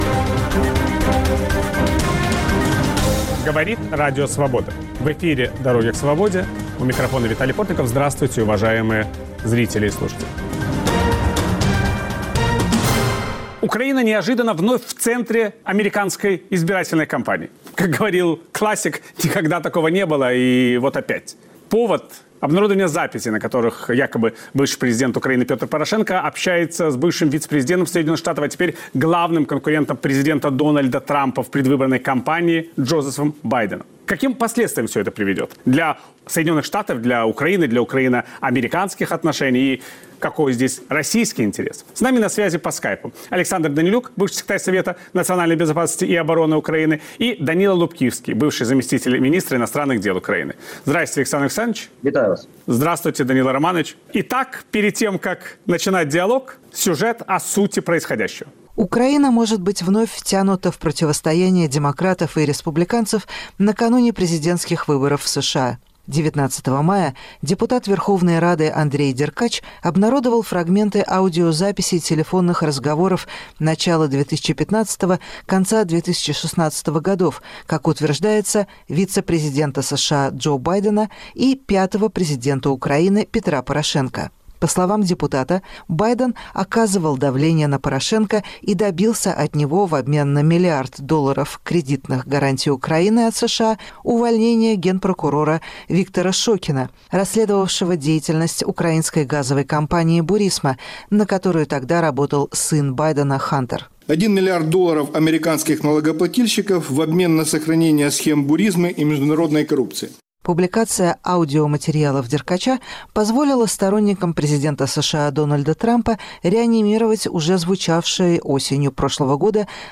Собеседники Виталия Портникова - Александр Данилюк, бывший секретарь Совета национальной безопасности и обороны Украины и Данило Лубкивский, бывший замминистра иностранных дел Украины.